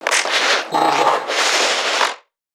NPC_Creatures_Vocalisations_Infected [92].wav